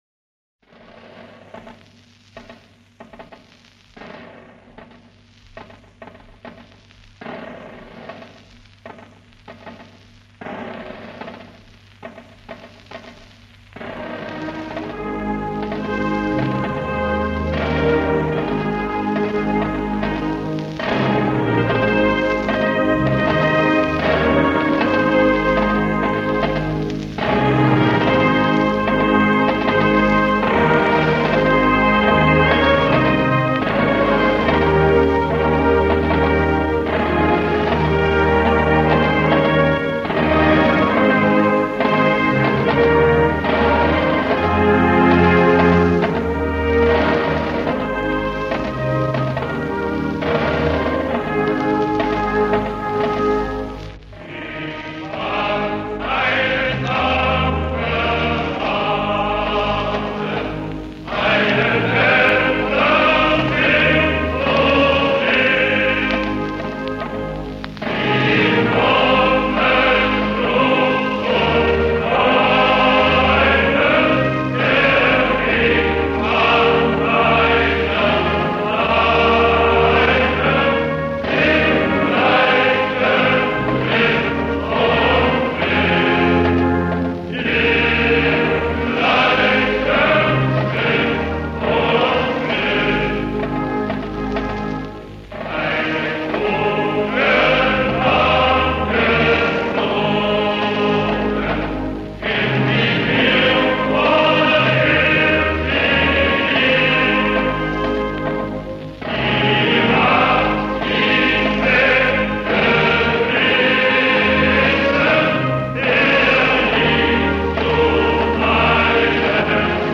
Zu dem von der Militärmusik intonierten Lied